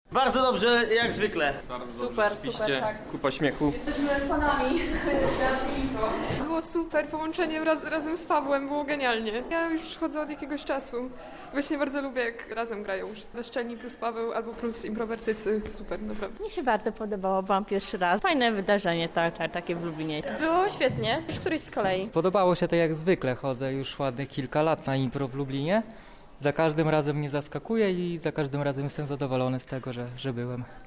pytała uczestników o ich wrażenia z wydarzenia.